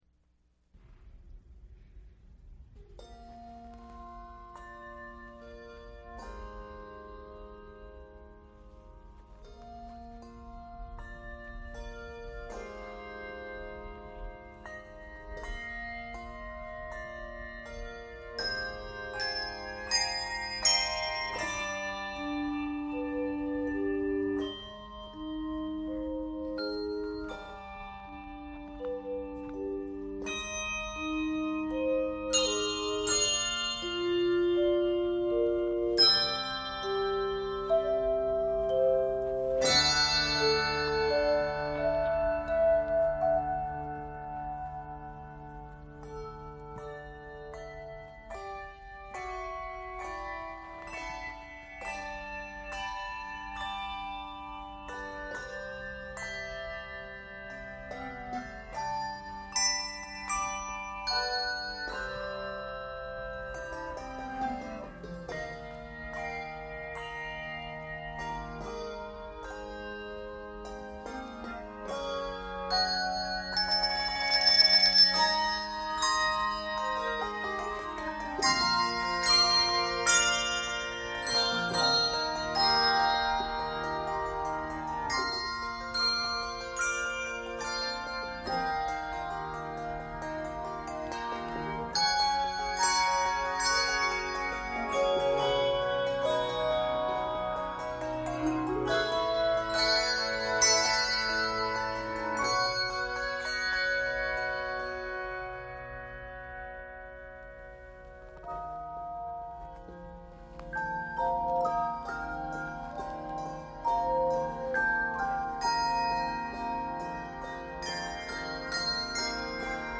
for advanced ensembles